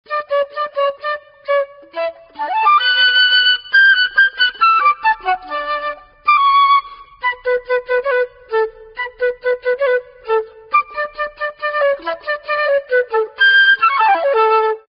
Extrait fl�te solo Son Histoire: La fl�te est un instrument tr�s ancien : on en trouve des mentions dans la Bible et des repr�sentations sur les bas-reliefs �gyptiens.
flute.mp3